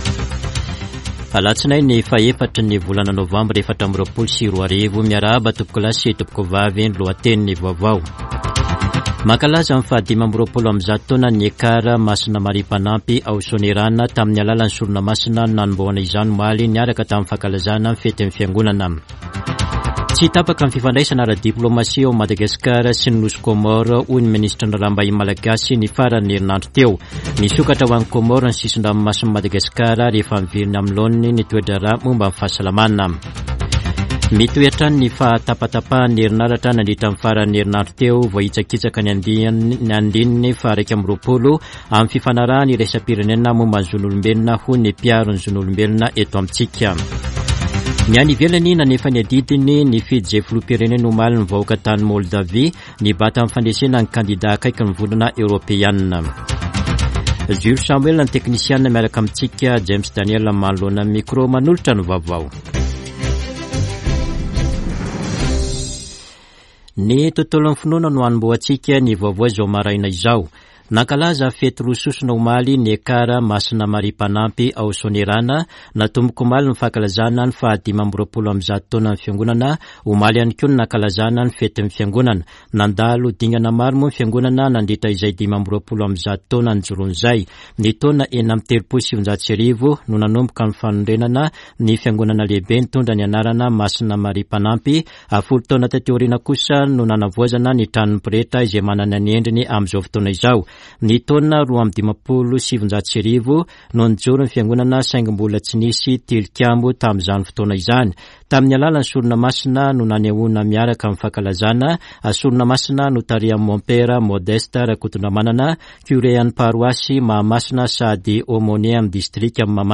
[Vaovao maraina] Alatsinainy 4 novambra 2024